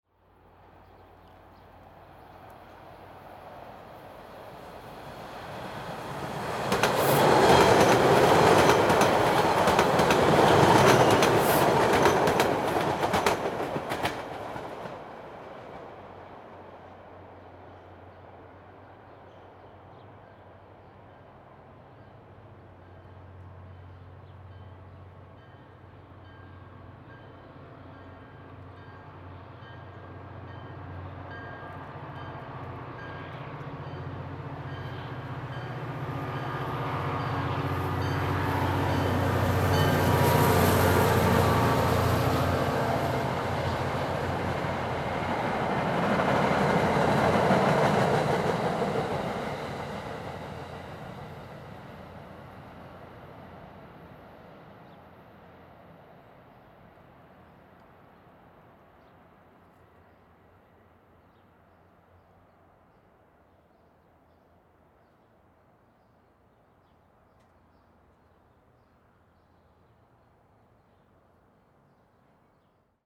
Trains: Left > Right then Right > Left
TONE-ORANGE COMMUTER TRAINS.mp3